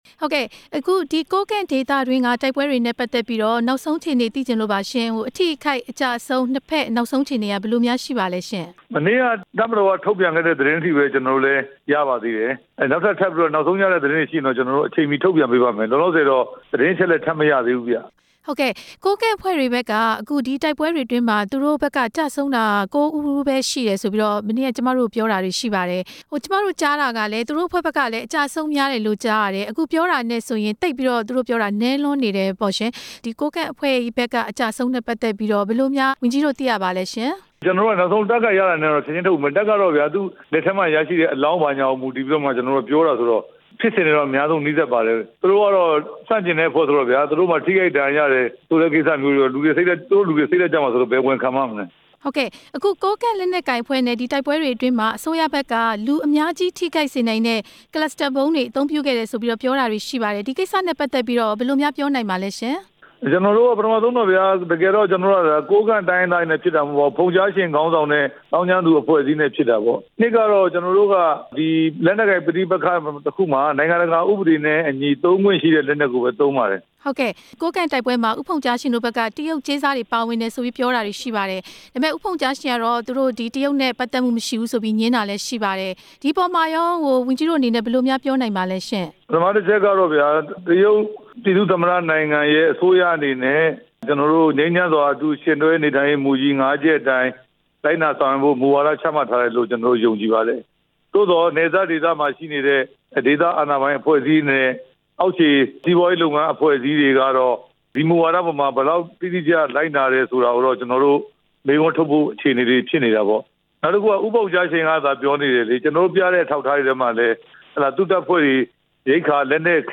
ကိုးကန့်ဒေသတိုက်ပွဲ သမ္မတပြောခွင့်ရ ဝန်ကြီး ဦးရဲထွဋ်နဲ့ မေးမြန်းချက်